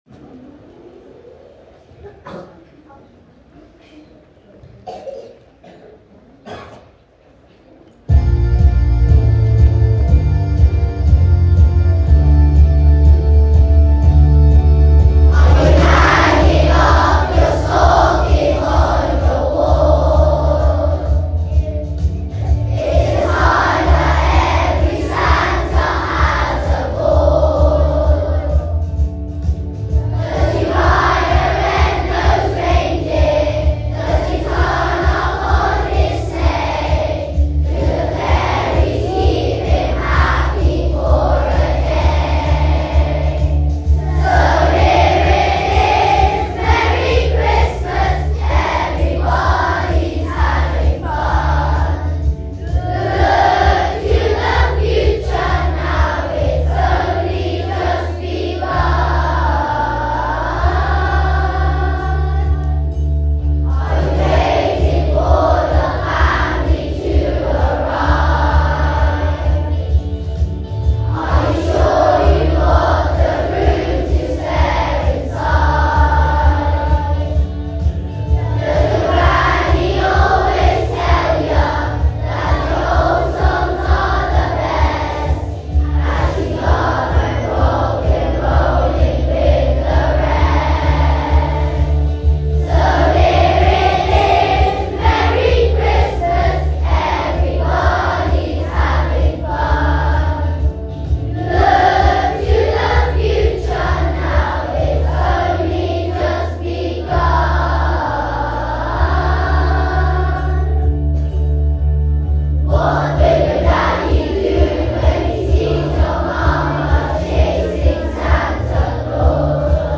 End of term festive assembly
End of term festive assembly 1st December 2024 Castlefield Festive singing December 24.m4a Our final assembly of 2024 involved some very enthusiastic singing of festive songs.